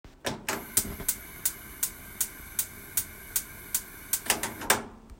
掃除前のコンロの音
掃除前のコンロの音.mp3